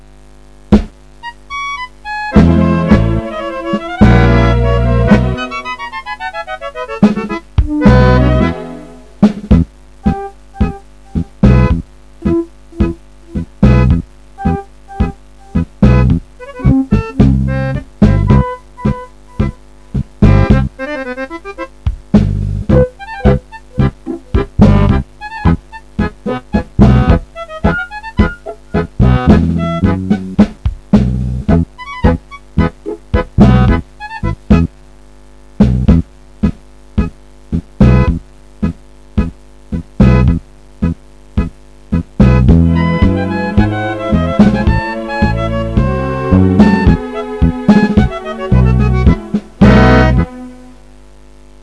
tango.wav